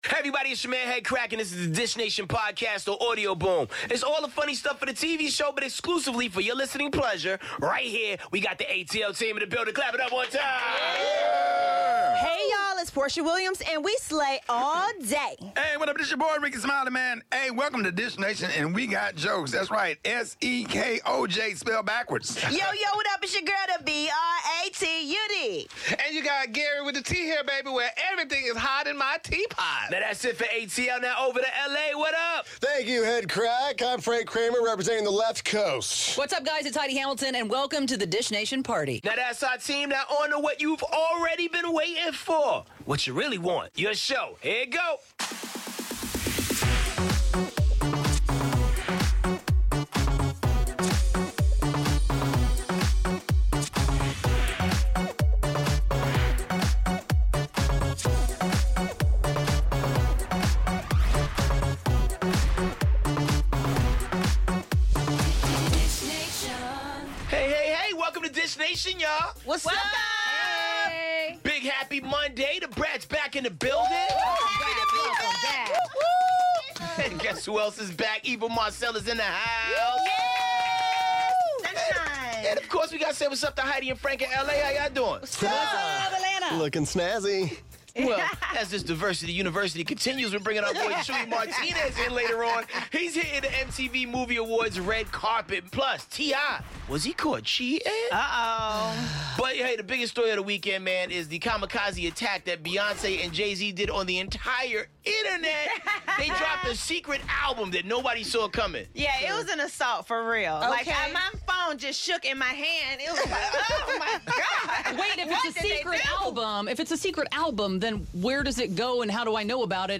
Guest host: Eva Marcille. Beyonce & Jay-Z drop a new album & video & we're on the red carpet at the 'MTV Movie & TV Awards.' Plus all the latest on Eminem, T.I., Heidi Klum & more.